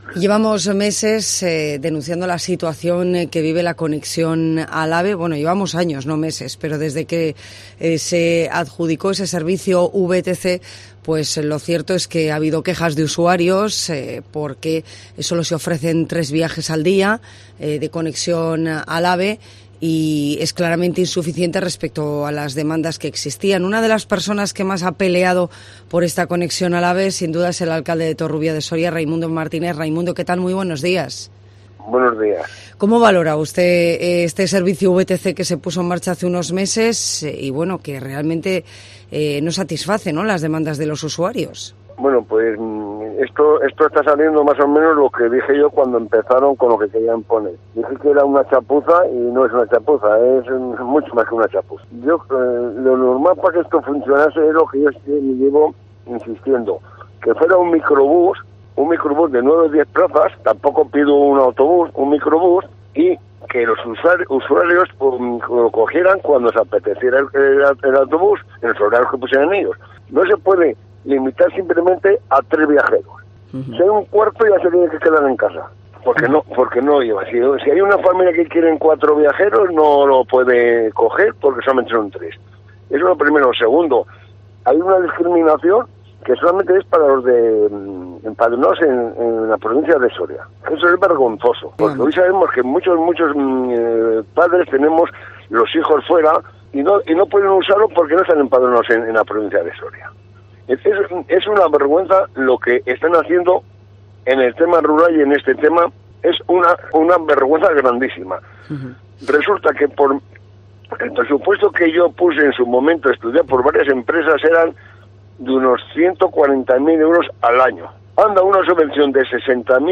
AUDIO: Escucha en COPE Soria a Raimundo Martínez, alcalde de Torrubia de Soria